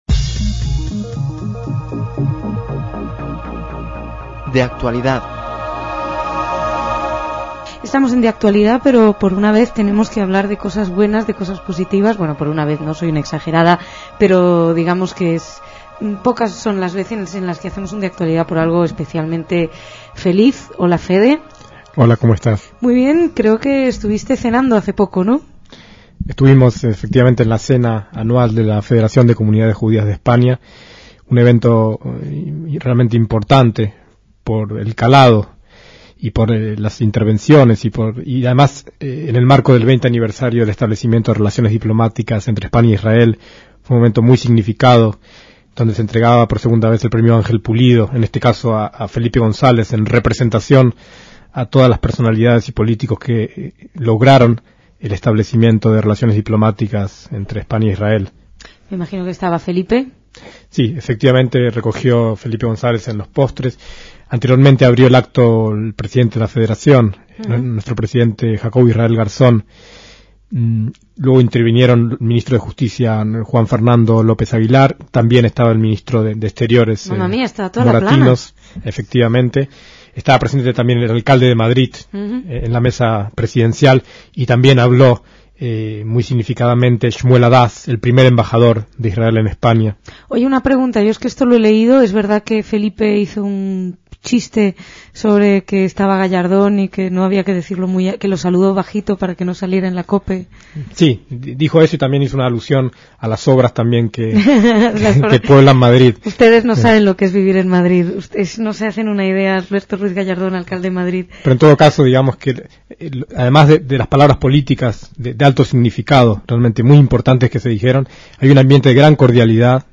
DECÍAMOS AYER (16/6/2006) - Un extracto del acto de entrega del premio Senador Ángel Pulido 2006 al ex presidente Felipe González, con ferencias de los principales oradores.